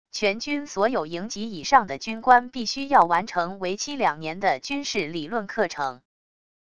全军所有营级以上的军官必须要完成为期两年的军事理论课程wav音频生成系统WAV Audio Player